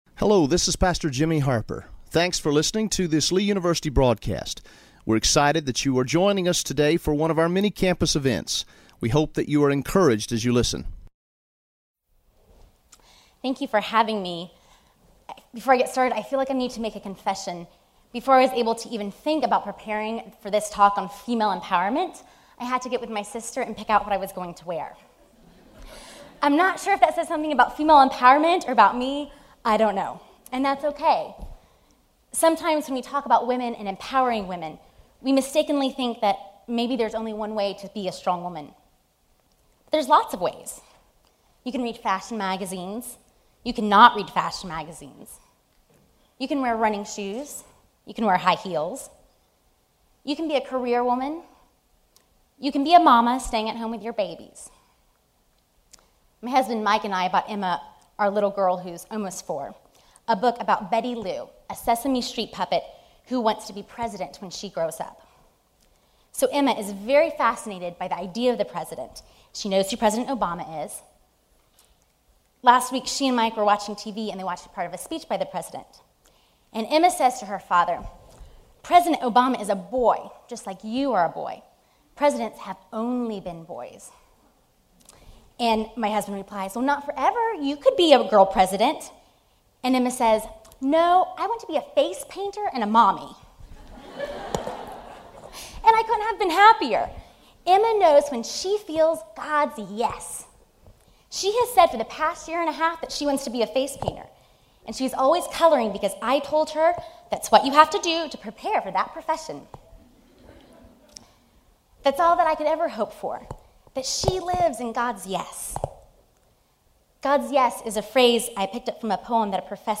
spoke at Lee University for International Women’s Day 2012.